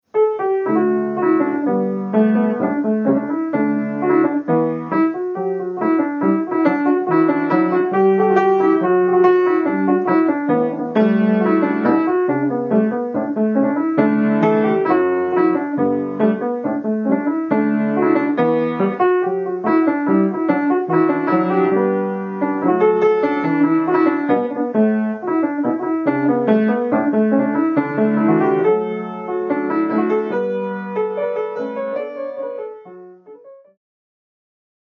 Piano Accompaniment - Easy Online Lessons - Online Academy of Irish Music
Piano.mp3